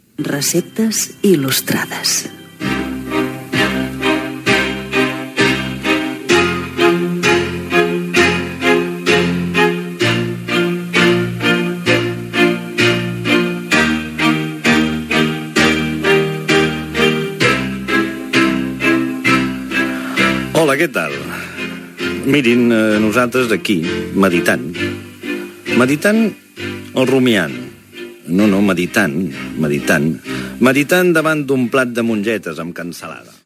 Careta del programa i comentari inicial
FM